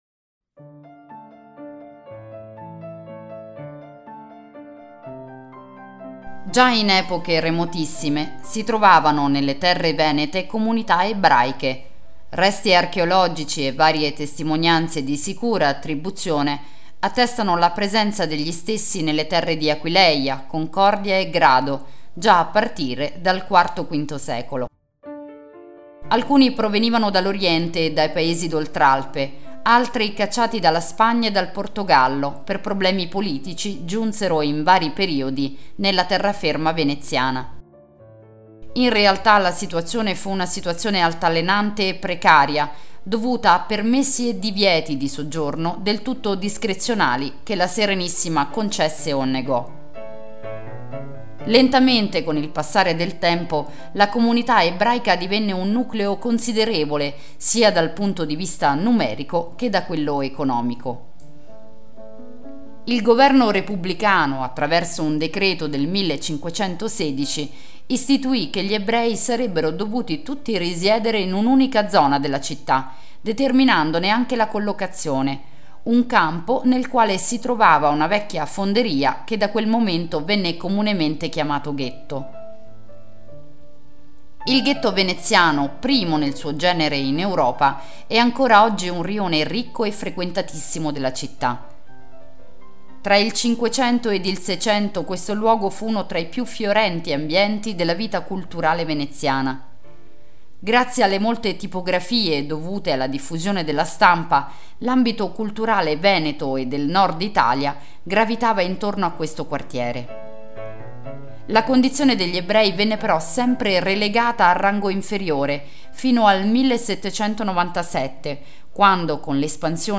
Audioguida Venezia – L’antico Ghetto Ebraico